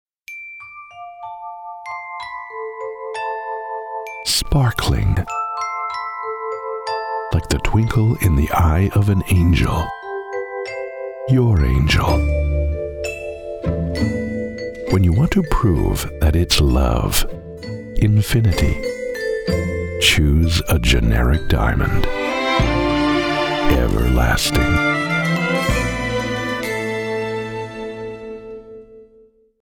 Mellow On Spec National Jewelry VO/writer